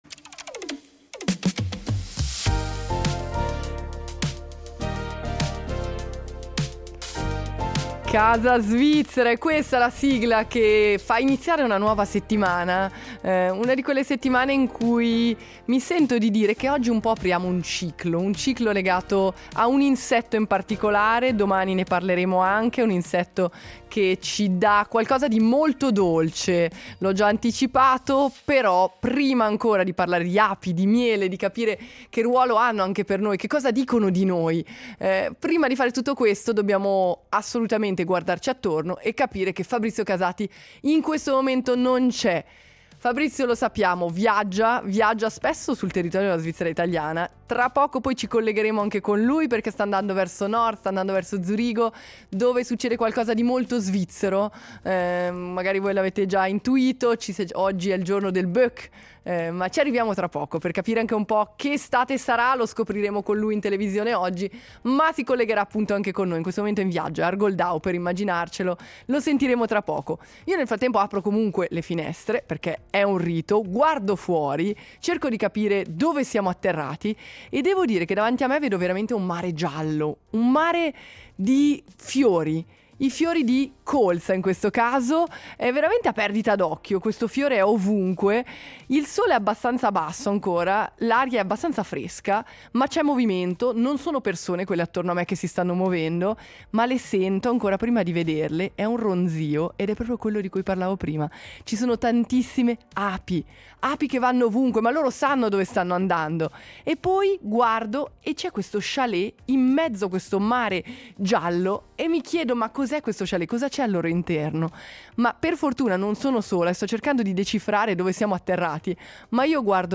Abbiamo aperto la finestra e prima ancora di vedere… abbiamo sentito: un ronzio continuo, preciso, vivo. Api ovunque.